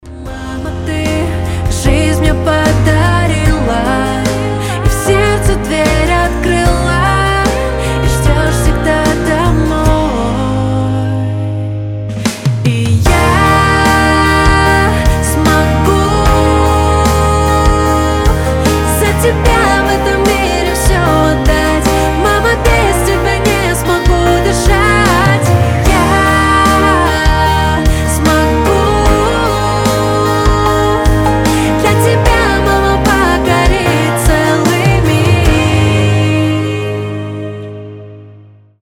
• Качество: 320, Stereo
душевные
добрые